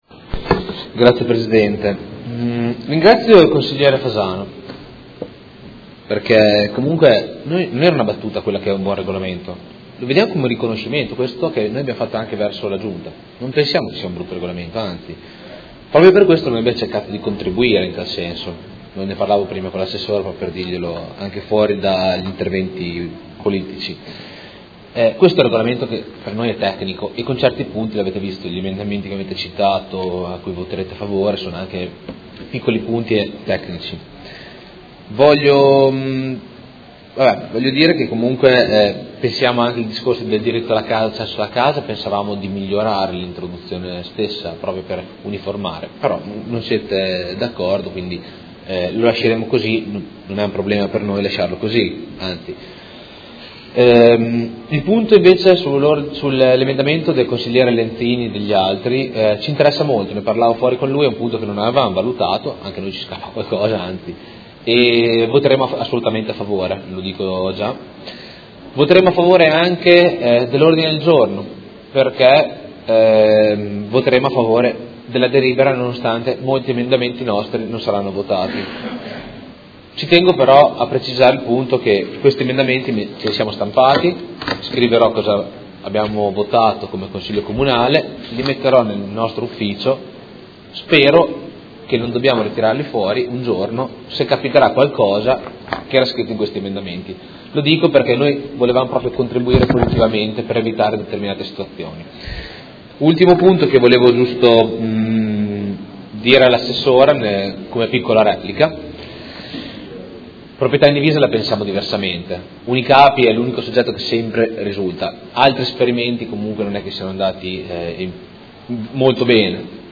Seduta del 17/05/2018. Dichiarazioni di voto su proposta di deliberazione: Regolamento Edilizia convenzionata e agevolata - Approvazione, emendamenti e Ordine del Giorno presentato dal Gruppo Consigliare PD avente per oggetto: Rafforzamento delle politiche pubbliche per l'accesso alla casa attraverso il nuovo regolamento per l'edilizia convenzionata e agevolata